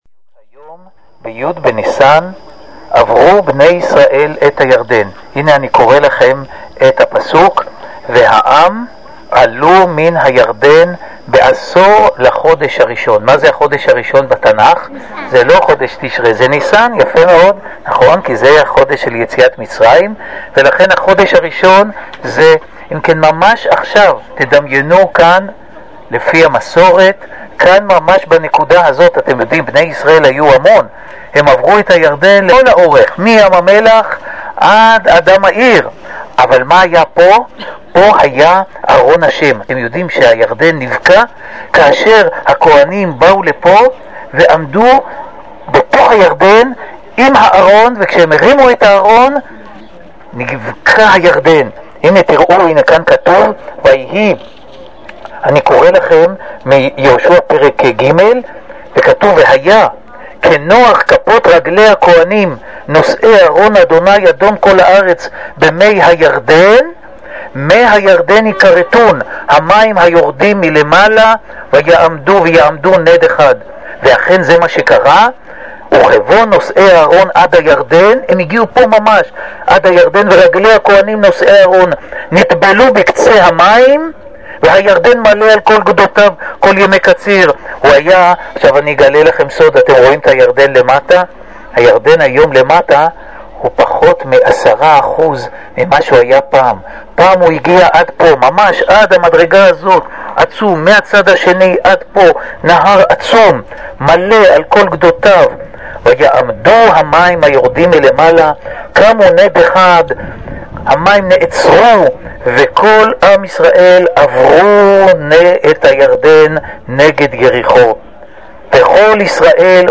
שיעורים לרגל י ניסן